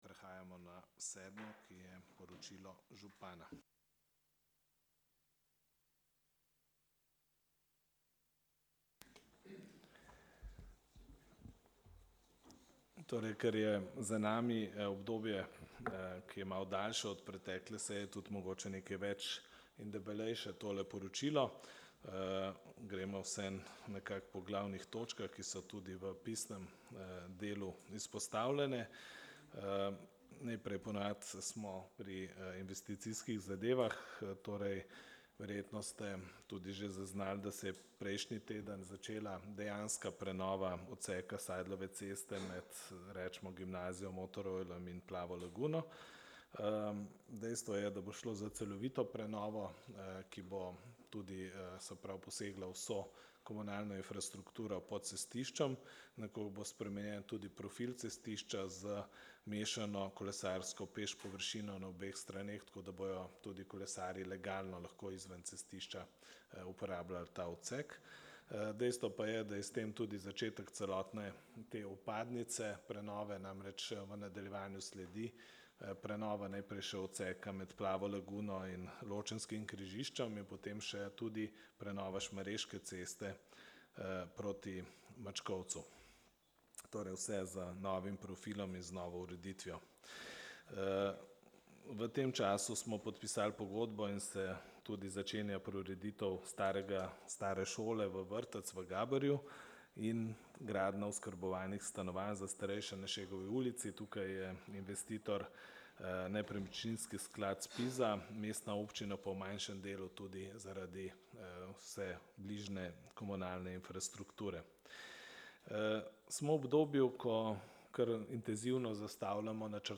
16. seja Občinskega sveta Mestne občine Novo mesto